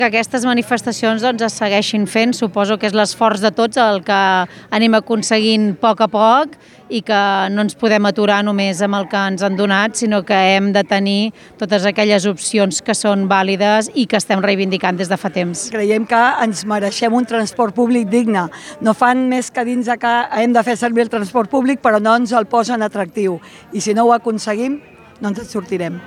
L'alcaldessa d'Argentona, Montse Capdevila, i la regidora de Mobilitat, Montse Cervantes, han expressat el seu suport a la caminada reivindicativa.